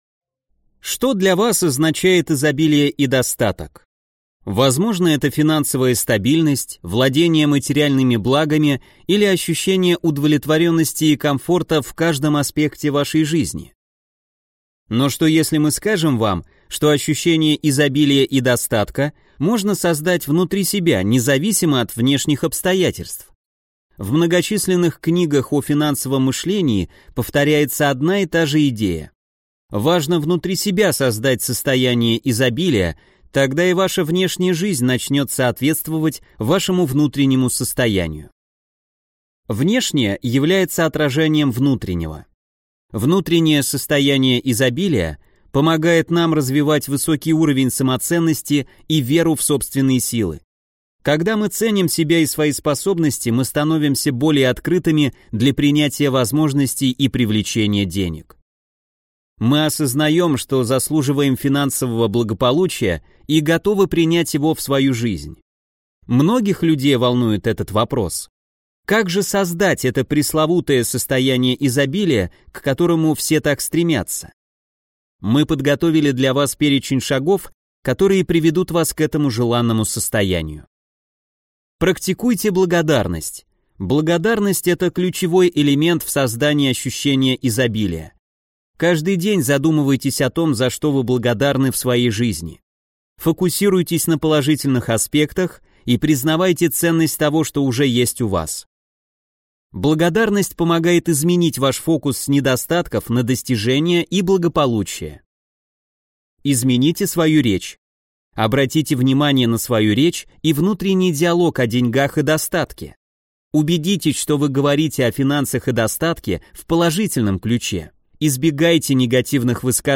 Аудиокнига Психология богатства | Библиотека аудиокниг